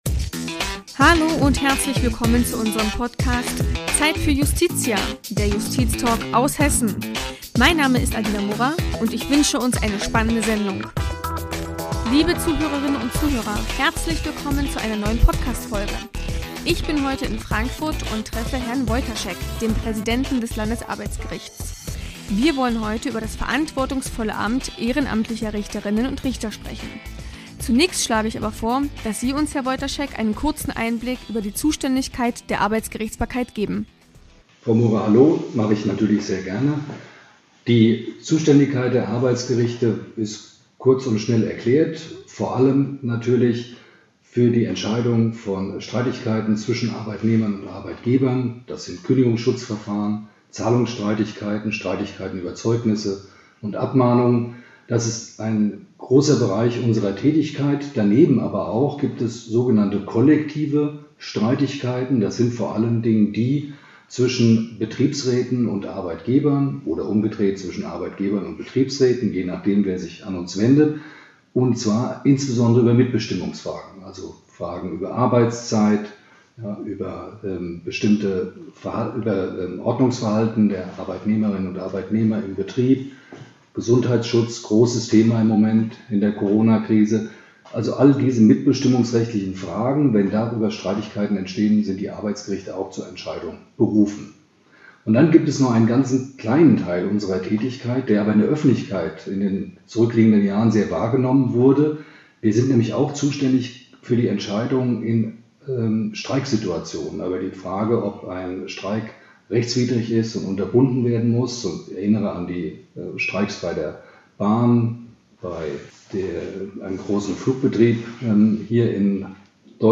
Frank Woitaschek ist Präsident des Hessischen Landesarbeitsgerichts. In dieser Folge stellt er das Amt ehrenamtlicher Richterinnen und Richter vor und erläutert den Unterschied zu Schöffinnen und Schöffen.